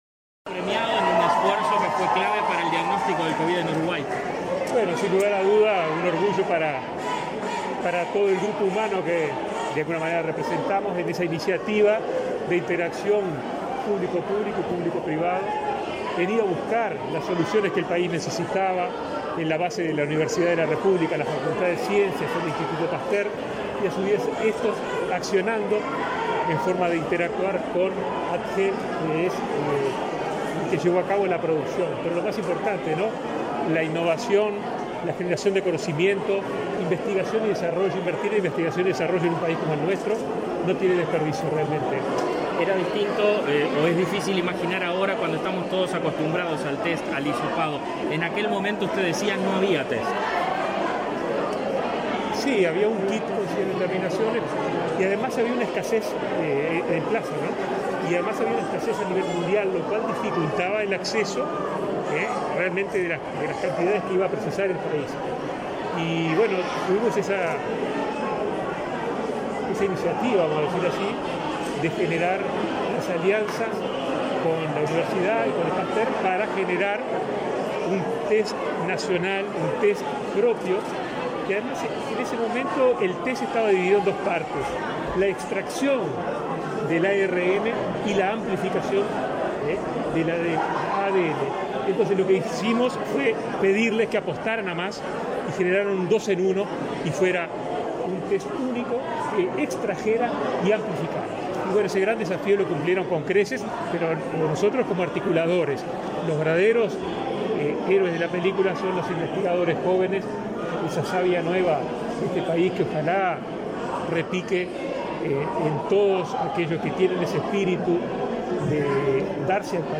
Declaraciones a la prensa del ministro de Salud Pública, Daniel Salinas
Declaraciones a la prensa del ministro de Salud Pública, Daniel Salinas 23/11/2021 Compartir Facebook X Copiar enlace WhatsApp LinkedIn Tras participar en la entrega de los premios NOVA, este 22 de noviembre, instancia a la que asistió el presidente Luis Lacalle Pou, el ministro Salinas efectuó declaraciones a la prensa.